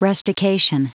Prononciation du mot rustication en anglais (fichier audio)
Prononciation du mot : rustication